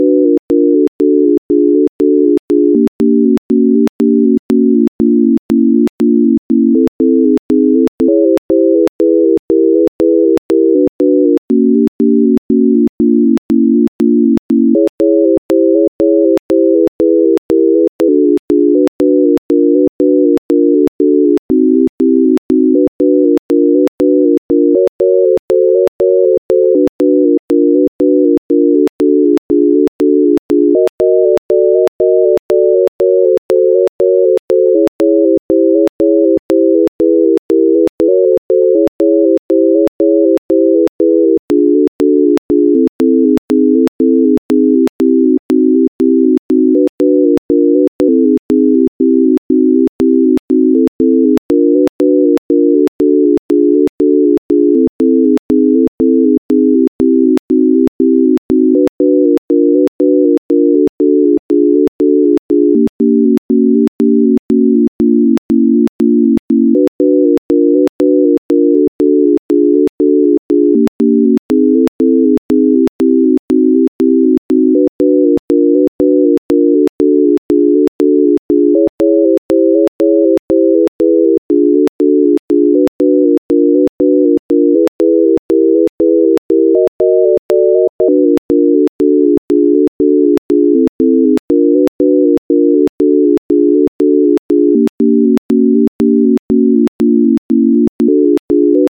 it sounds like a recording of someone jamming on a jazz organ but the tape is kinda stretched out and they're only playing eerie chords but they're like really into it